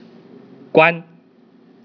close_sound
close_sound.wav